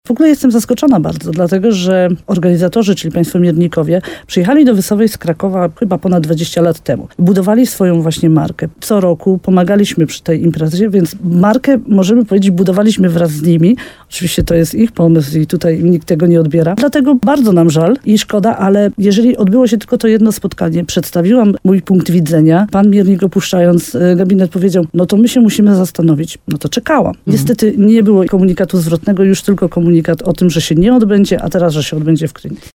Do decyzji o przeniesieniu imprezy odniosła się w programie Słowo za Słowo na antenie RDN Nowy Sącz wójt gminy Uście Gorlickie Ewa Garbowska-Góra.